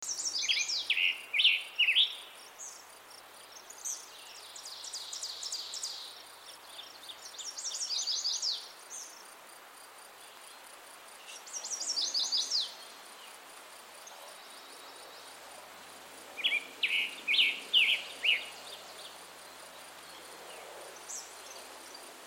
See if you can identify the birds singing, all recorded during the trip: